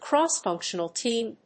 クロスファンクシュンチーム